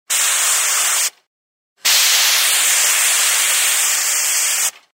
Звуки пара